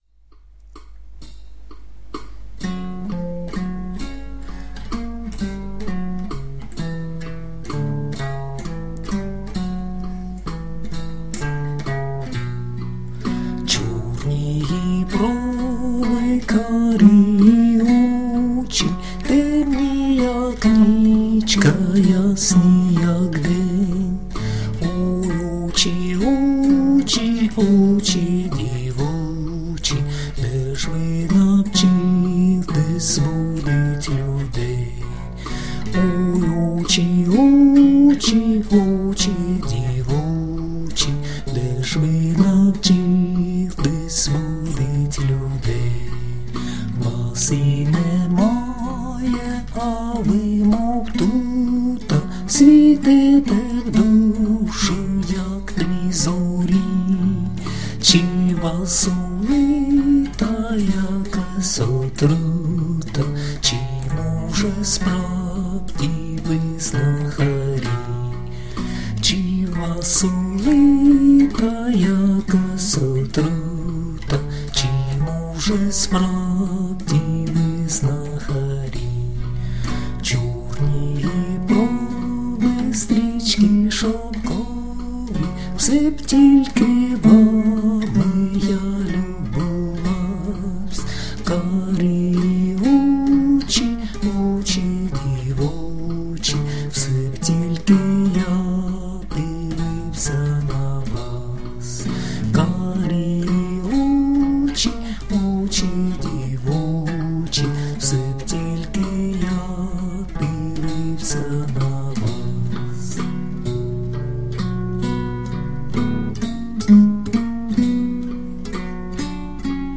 ../icons/chtobylo.jpg   Українська народна пiсня